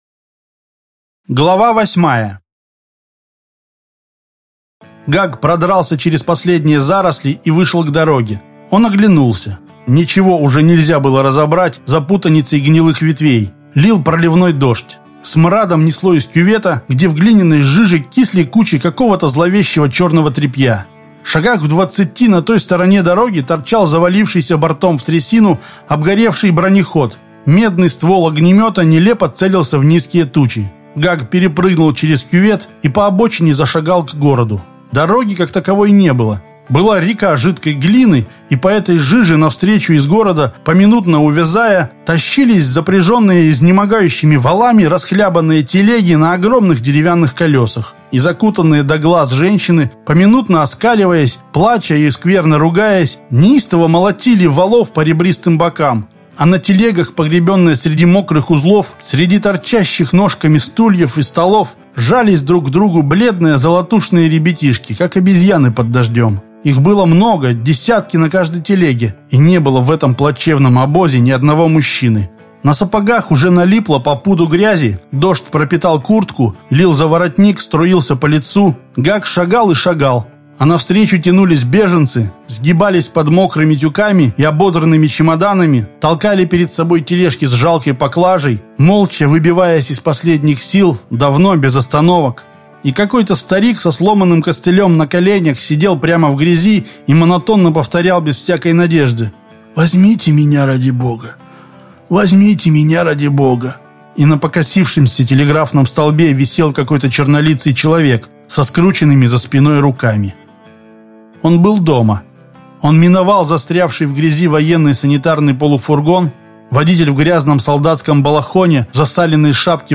Аудиокнига Парень из преисподней. Часть 8.